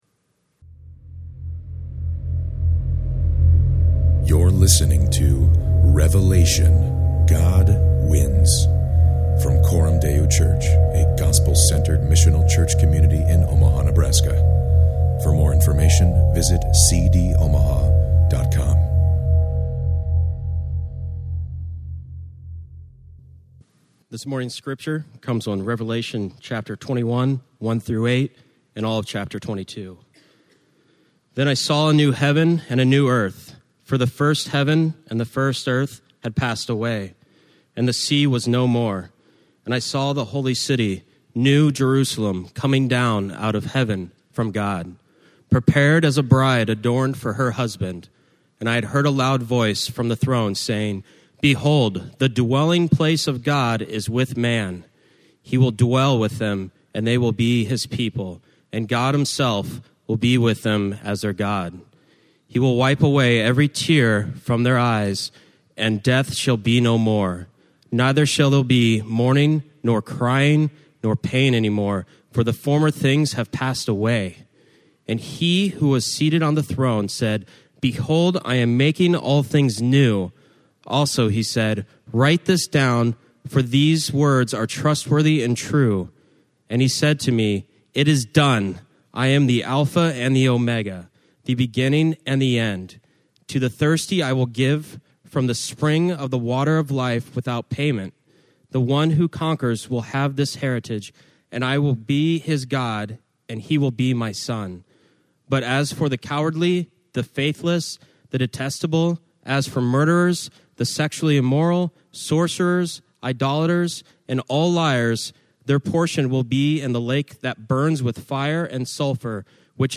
Revelation: God Wins is a 14 week preaching series exploring the book of Revelation.